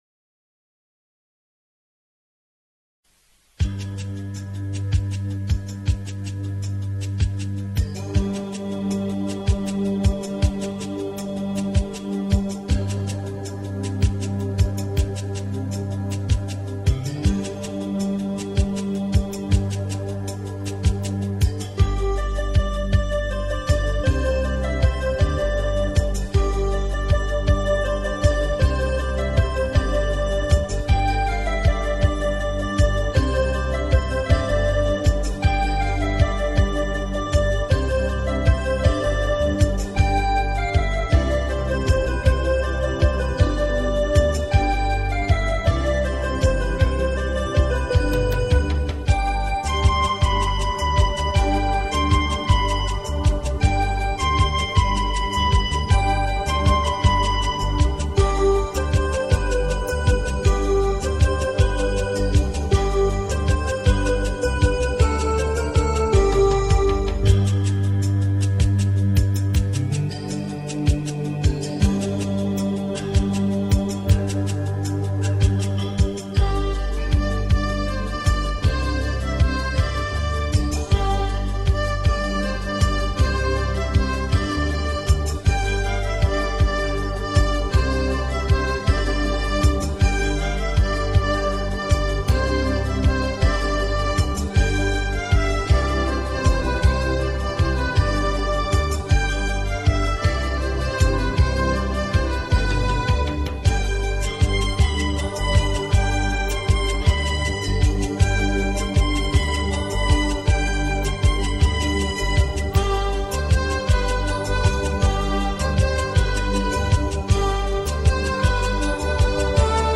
سرودهای انقلابی
بی‌کلام